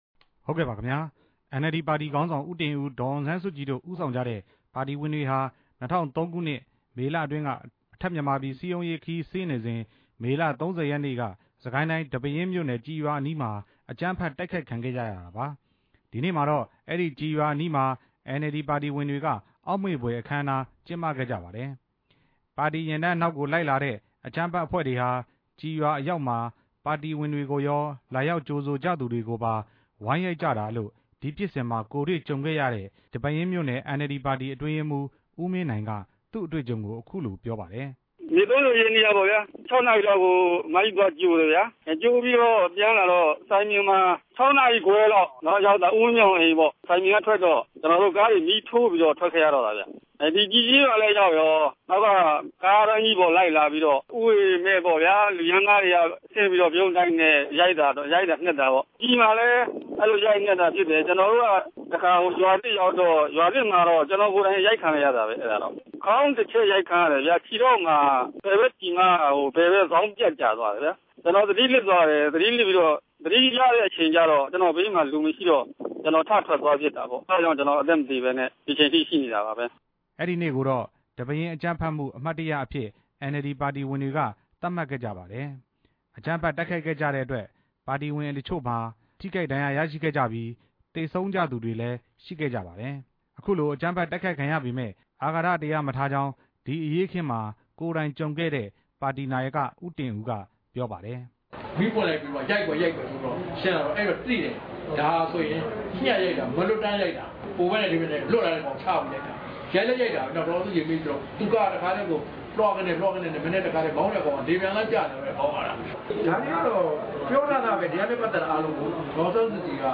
ဒီပဲယင်း ၁ဝ နှစ်မြောက် နှစ်ပတ်လည်နေ့နဲ့ ပတ်သက်ပြီး တင်ပြချက်